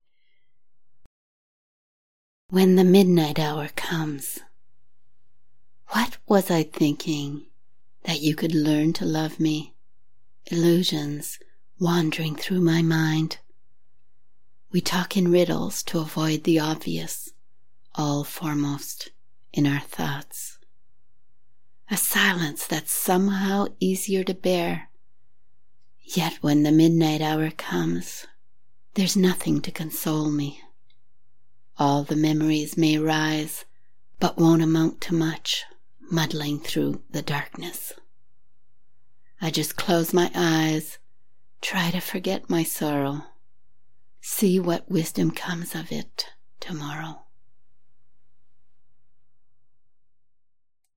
Sad theme but beautiful thoughts expressed in the sound of your voice.
I love your voice, very deep and emotive